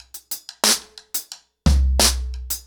ROOTS-90BPM.31.wav